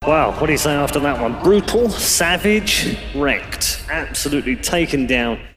Worms speechbanks
Fatality.wav